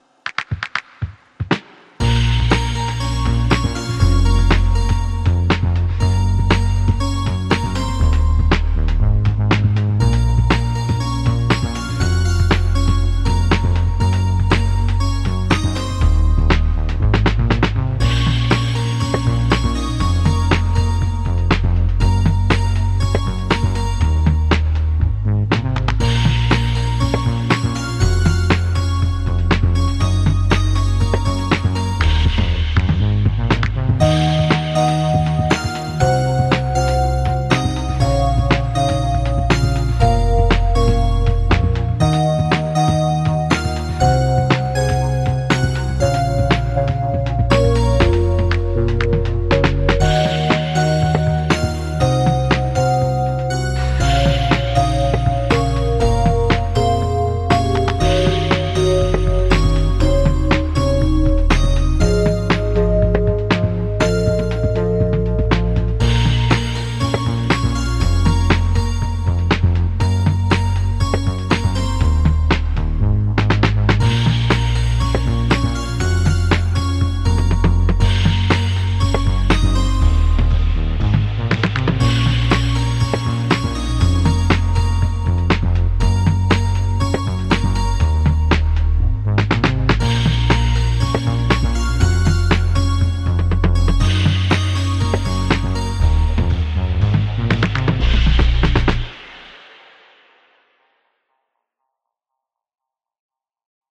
made to sound like there’s an encroaching deadline